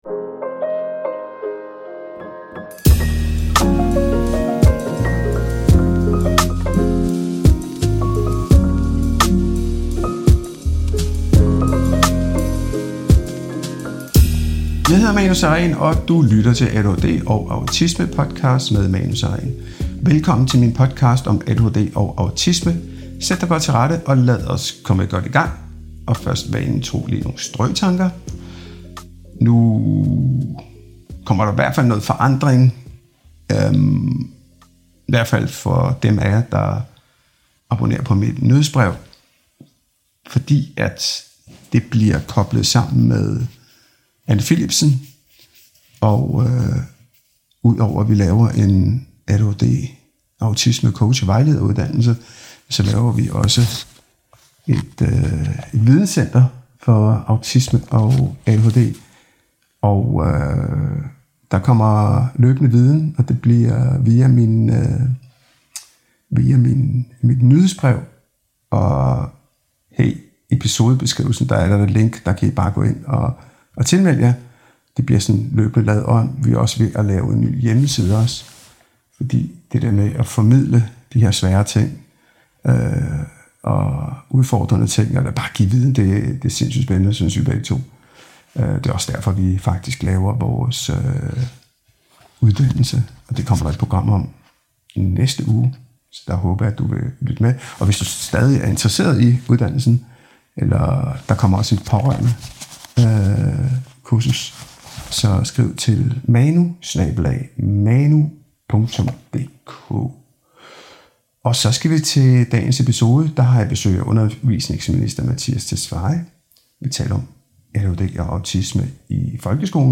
Lyt med til en ærlig og skarp samtale om børn, diagnoser, opdragelse – og om hvilket ansvar vi som voksne og som samfund tager for de børn, der falder igennem i dag.
I denne episode har jeg besøg af undervisningsminister Mattias Tesfaye. Vi taler om ADHD og autisme i folkeskolen – og om, hvordan vi konkret kan skabe en skole, hvor børn med neurodivergens ikke bare overlever hverdagen, men faktisk trives og får mulighed for at lykkes.